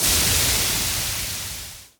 Waterspray 1.wav